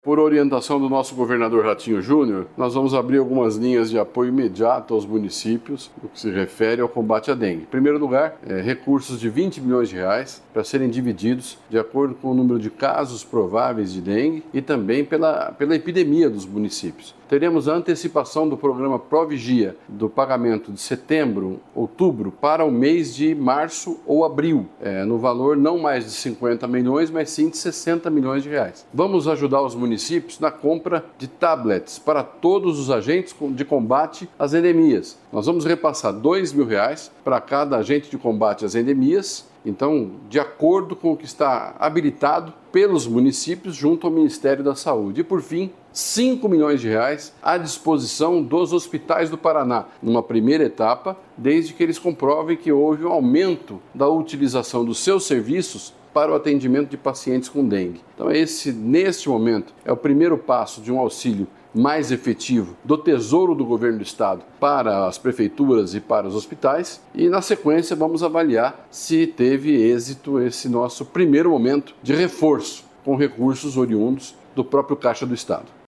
Sonora do secretário da Saúde, Beto Preto, sobre a liberação de R$ 93 milhões para ampliar enfrentamento à dengue